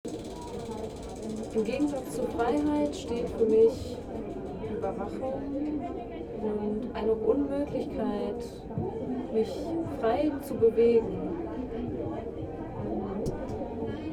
Ein Fest für die Demokratie @ Bundeskanzleramt, Berlin